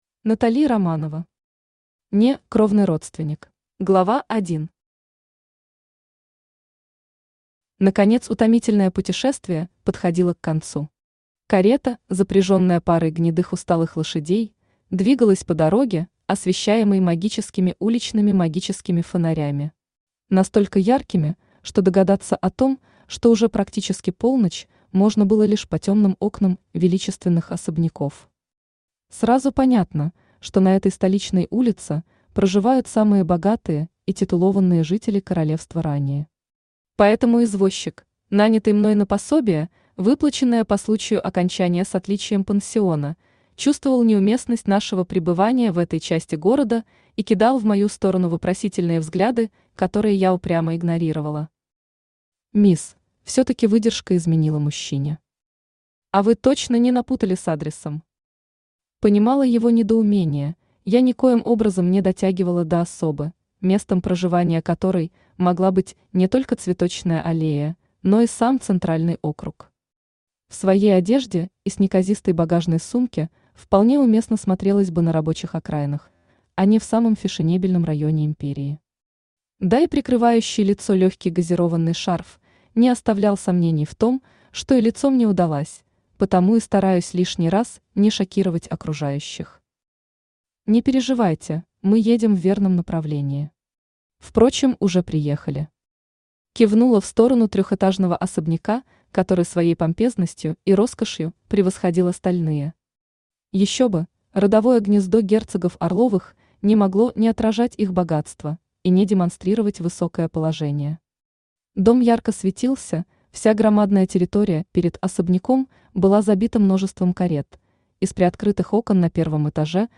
Аудиокнига (Не)кровный родственник | Библиотека аудиокниг
Aудиокнига (Не)кровный родственник Автор Натали Романова Читает аудиокнигу Авточтец ЛитРес.